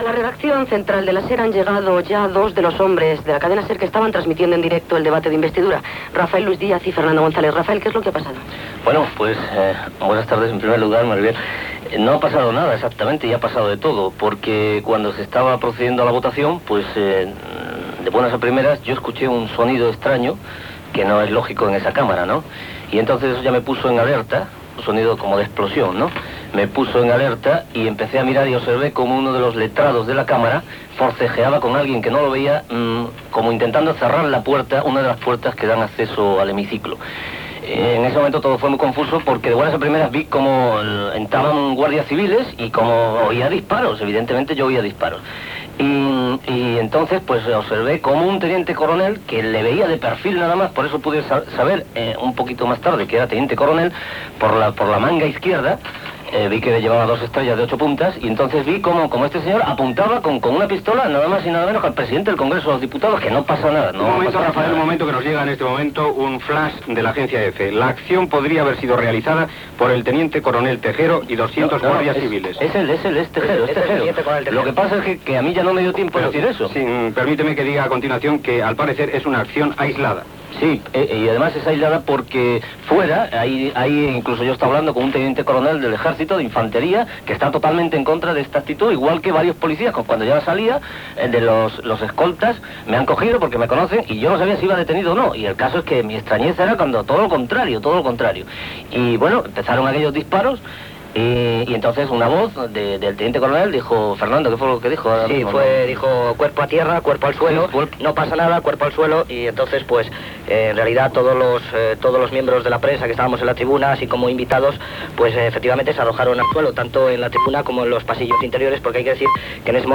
Al vespre d'aquell dia, els dos periodistes de la SER, que estaven al Congrés de Diputats quan va entrar la Guardia Civil per fer un cop d'estat, expliquen els fets viscuts i el que han vist a l'exterior de l'edifici
Informatiu